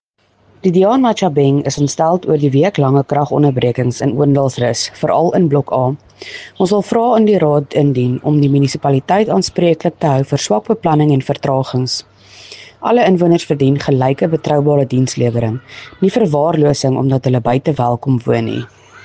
Afrikaans soundbites by Cllr Estelle Dansey and